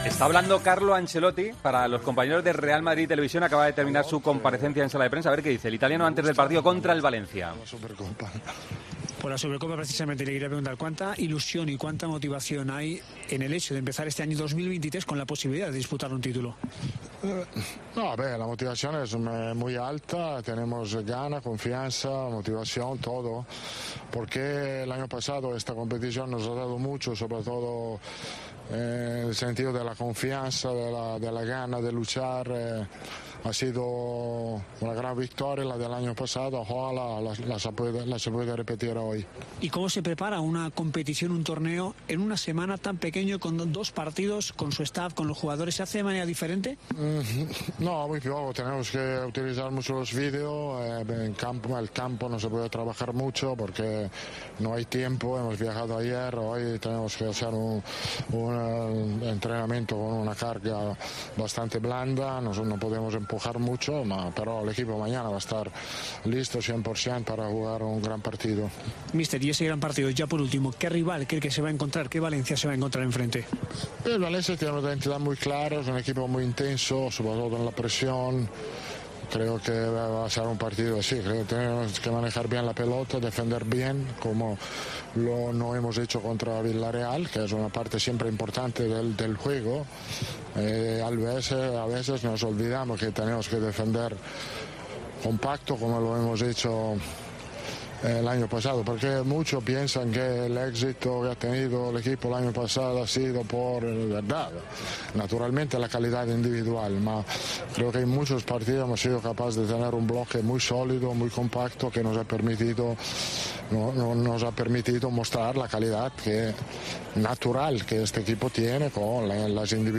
"Era previsible no estar a tope en este momento. Es verdad que las señales del entrenamiento eran buenas, me han sorprendido un poco el partido contra el Villarreal, las sensaciones eran mucho mejores. El partido ha mostrado otra cara. Tenemos que volver a hacer las cosas bien; el aspecto defensivo no ha sido bueno y tenemos que trabajar. Este equipo sabe defender muy bien, mejor de lo que lo ha hecho ante el Villarreal", declaró en rueda de prensa.